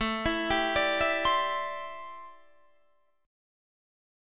Listen to Am11 strummed